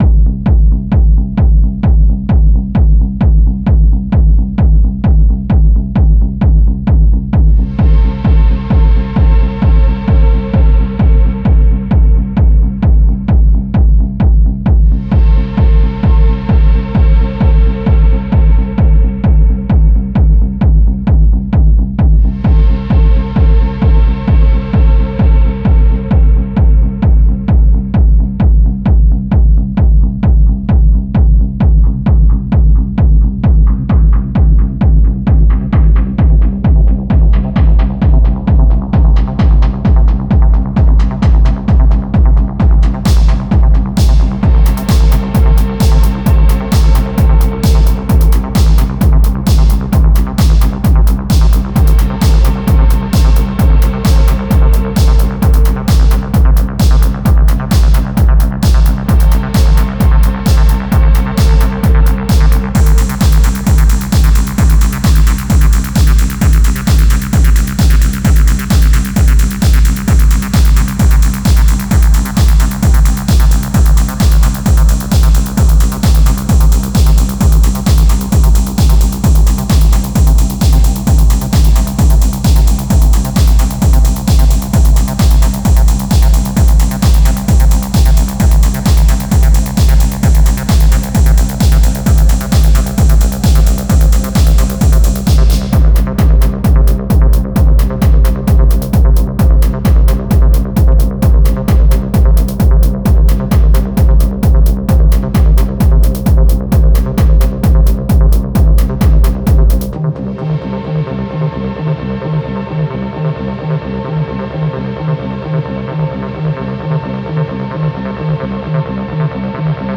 دانلود آهنگ اسیدی لایت